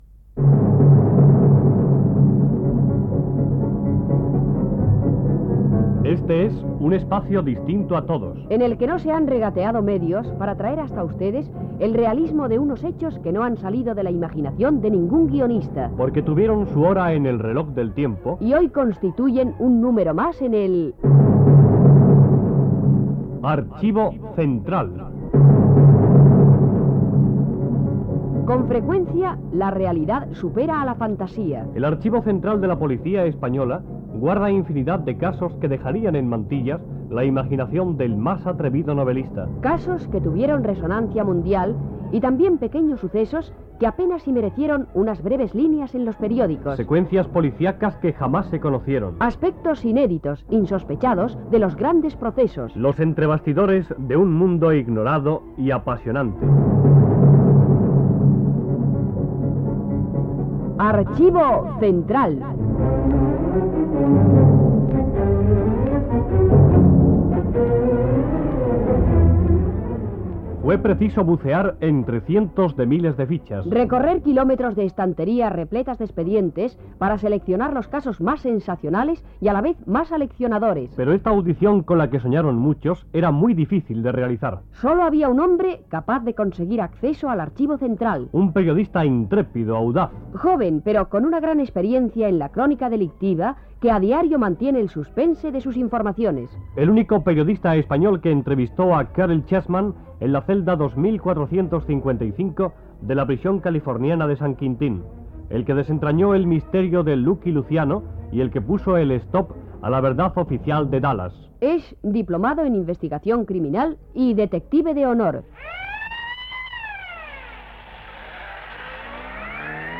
Careta del programa i espai dedicat a la reconstrucció dramatitzada del "cas de la via morta".
Info-entreteniment